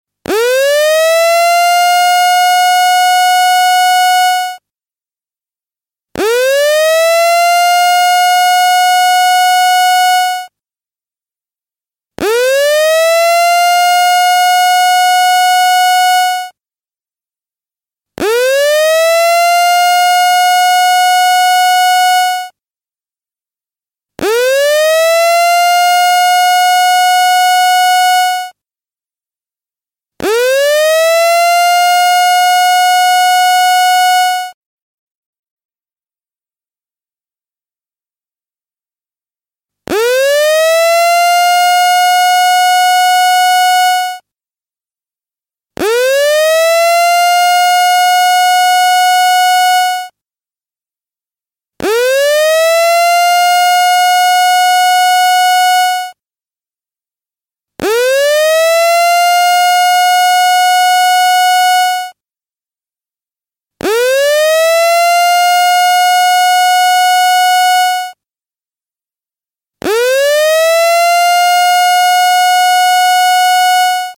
Download Emergency Siren sound effect for free.
Emergency Siren